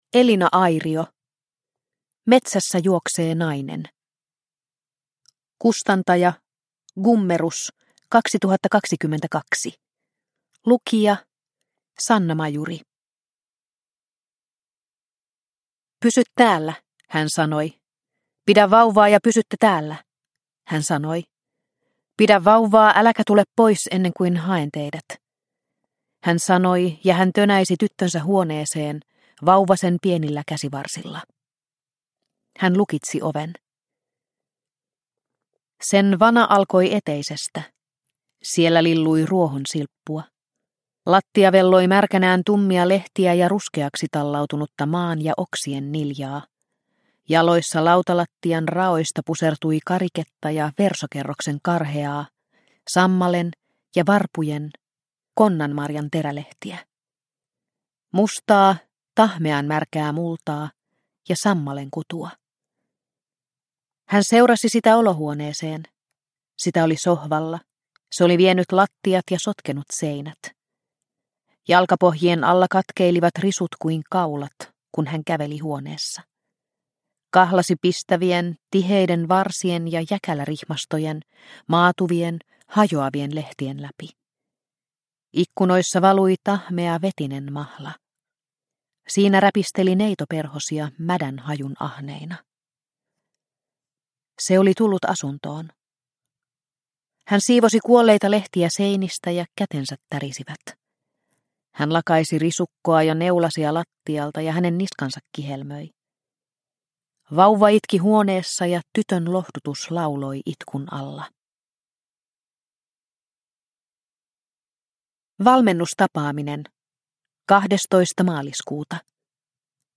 Metsässä juoksee nainen – Ljudbok – Laddas ner